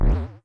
Bounce2.wav